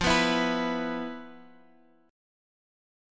E5/F chord